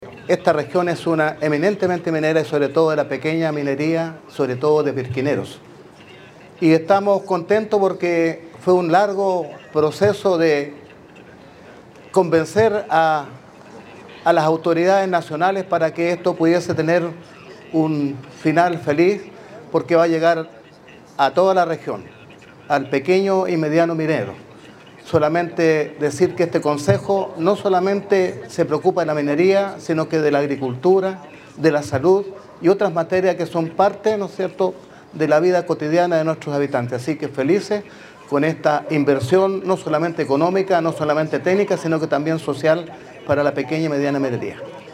Por su parte, el consejero regional, Lombardo Toledo, presidente de la comisión de Minería, Energía y Medio Ambiente, valoró el impacto del programa en el desarrollo del sector.
CORE-LOMBARDO-TOLEDO.mp3